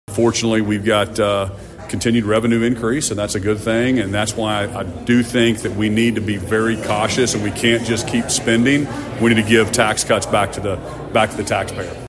CLICK HERE to listen to commentary from Governor Stitt.